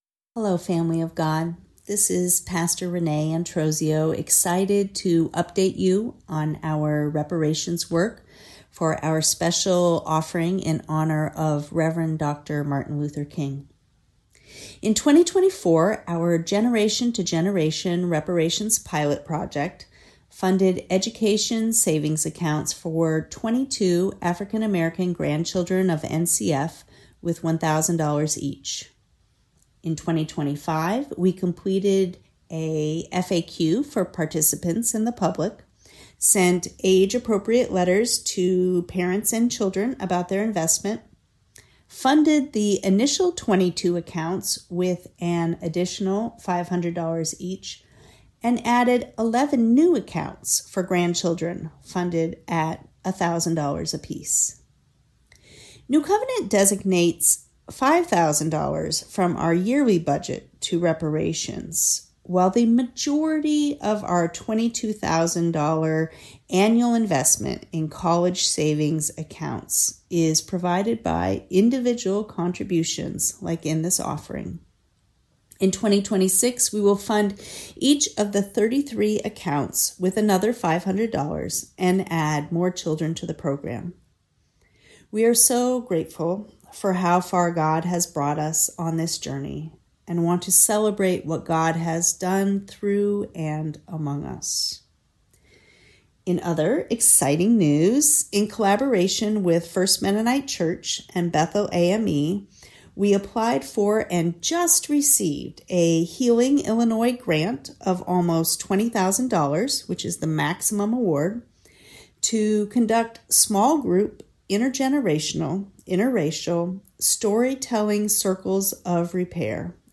Reparations Update